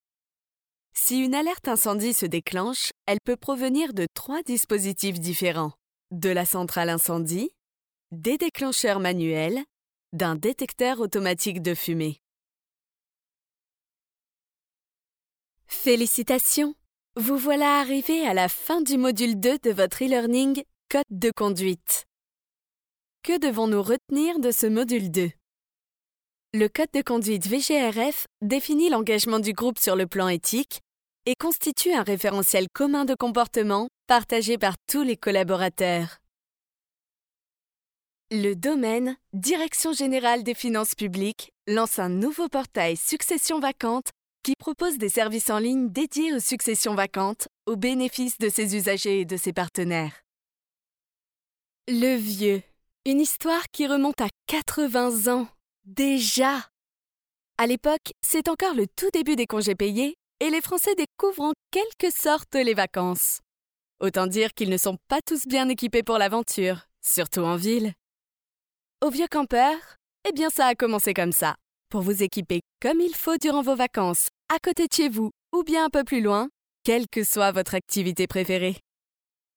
Enregistrement de voix-off féminine
en home studio
INSTITUTIONNEL
Enregistrement de voix pour des vidéos institutionnelles
Dans ce cas là, je vous envoie les fichiers nettoyés (sans bruit de bouche) prêts à l’emploi.